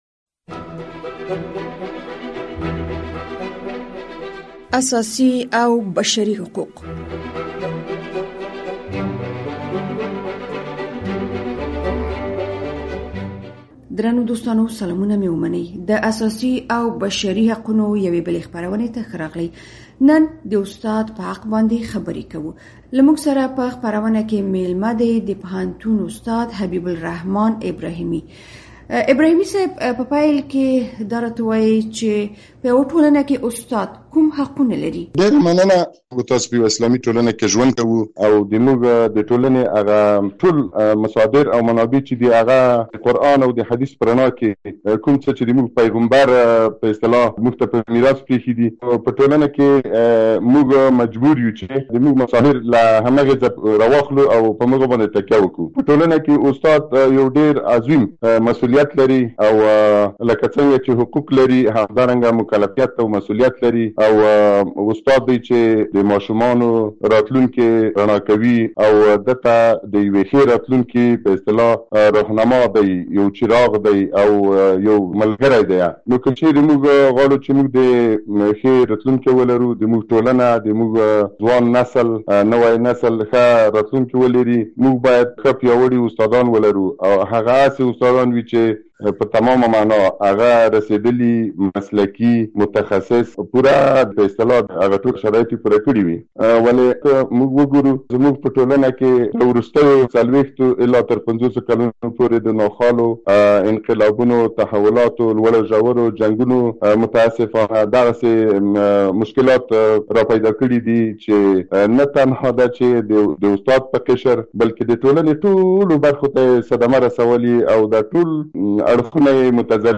د ازادي راډیو اوونیز د اساسي او بشري حقونو په پروګرام کې د افغان ښوونکو د ژوند اوسنی حالت څېړل شوی دی. په پیل کې به واورئ چې، ښوونکي د خپل ژوند په اړه څه وایي او ولې د افغان ښوونکو حقونه نه ورکول کېږي.